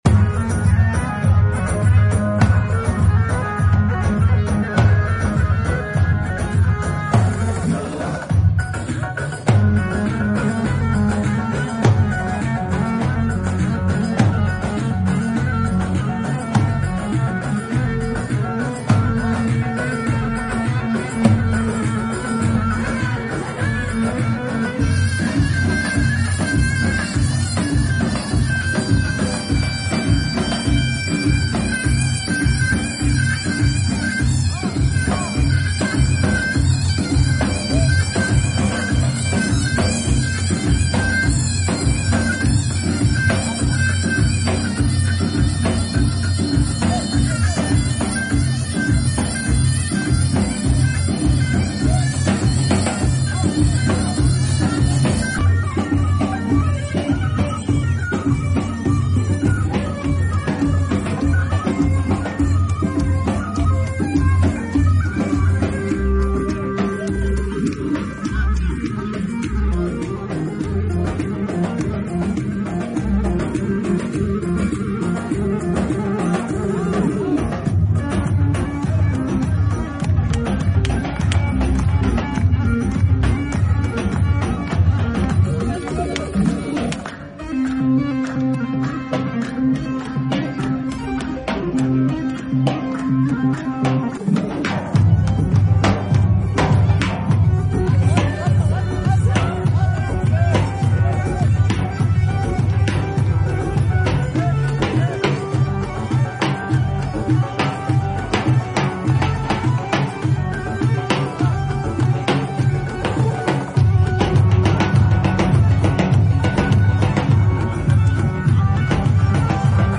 Harika bir Atmosfer ELBİSTAN ♥👏 Biz bu güzel düğüne doyamadık